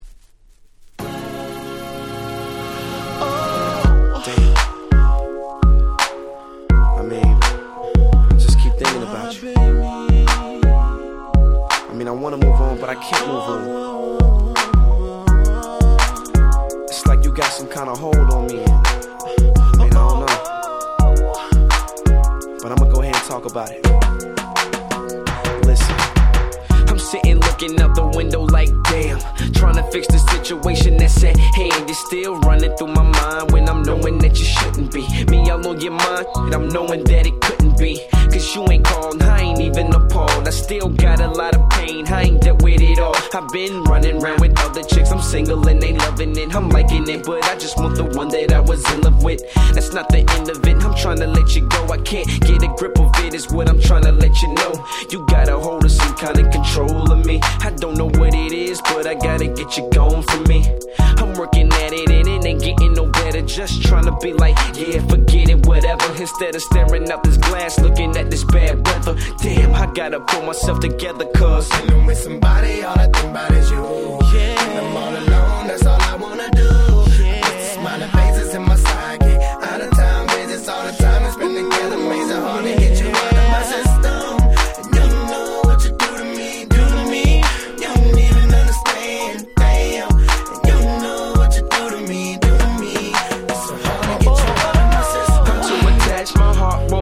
07' Super Hit Hip Hop !!
メロウでGroovyな1曲です！